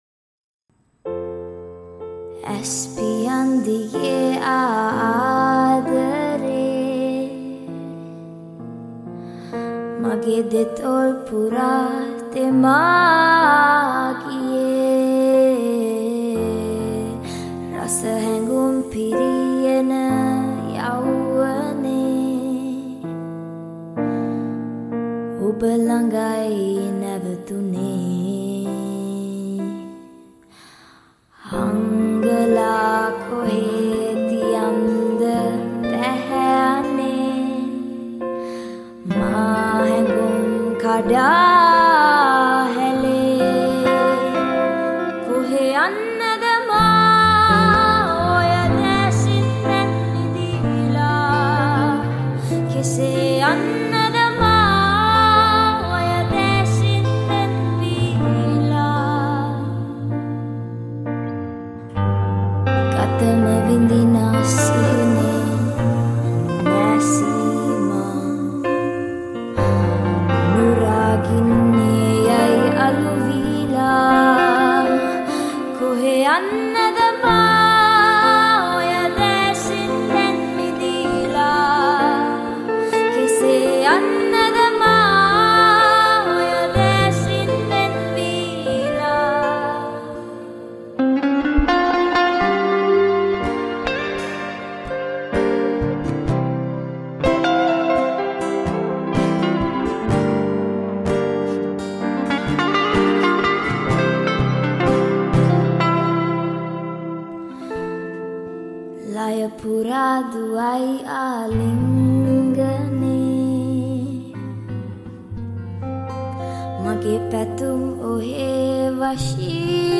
High quality Sri Lankan remix MP3 (3.7).
Covers